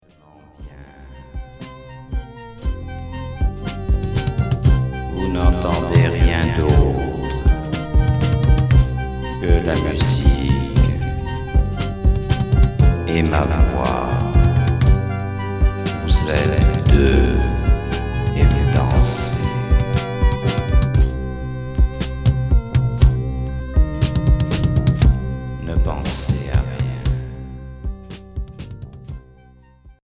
Expérimental hypnotique